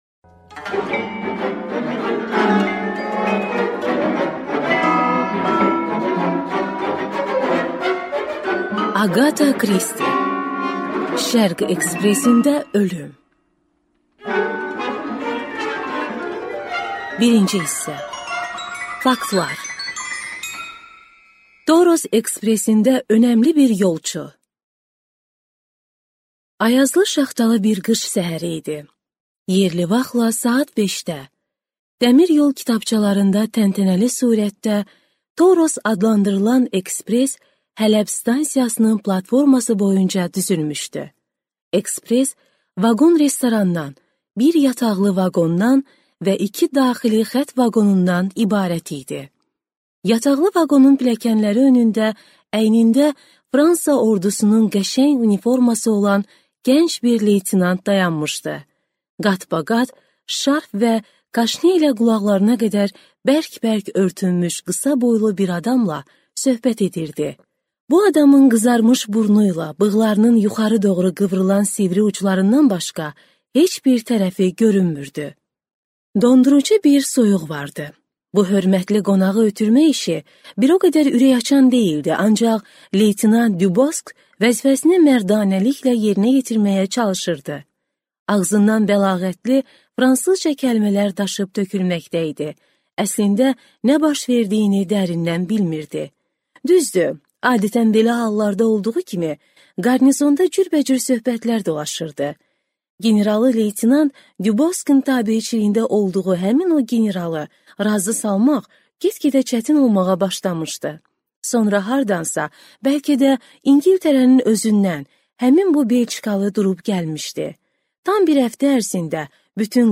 Аудиокнига Şərq ekspresində ölüm | Библиотека аудиокниг